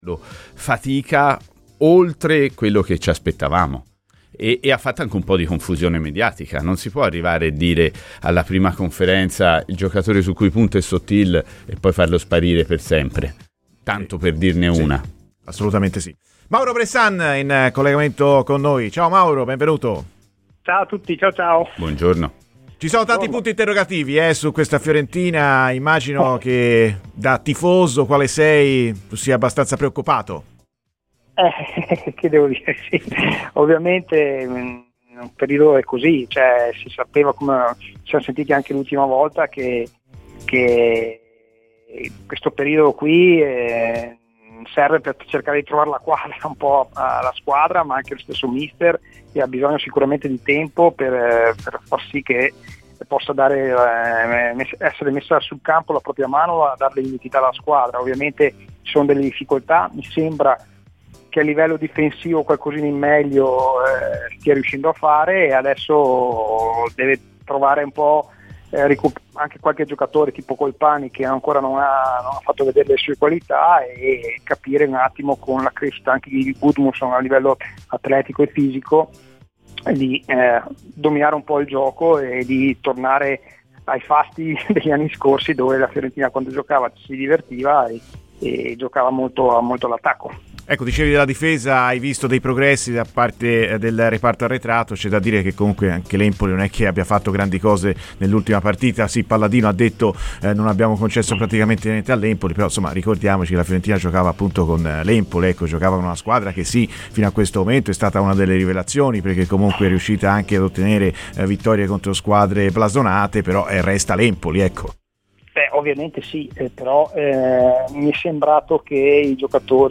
è intervenuto ai microfoni di Radio FirenzeViola durante "Viola amore mio"